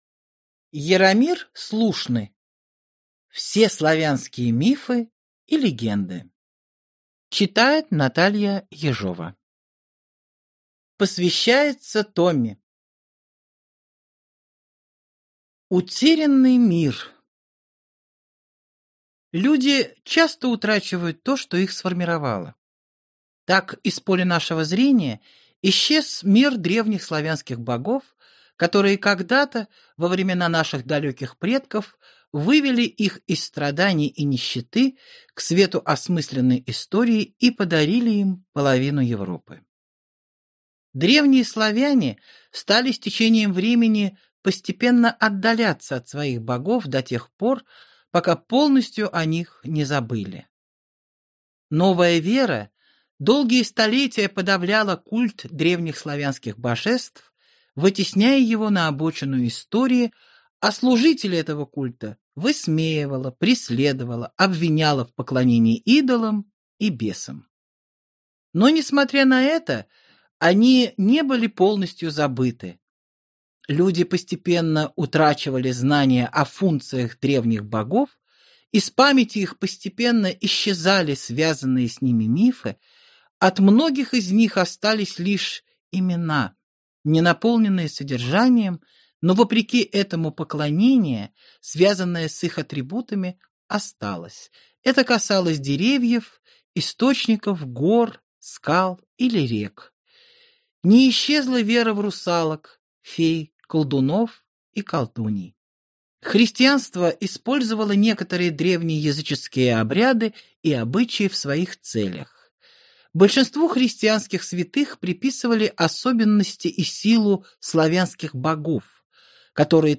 Аудиокнига Все славянские мифы и легенды | Библиотека аудиокниг